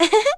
Juno-Vox_Happy1.wav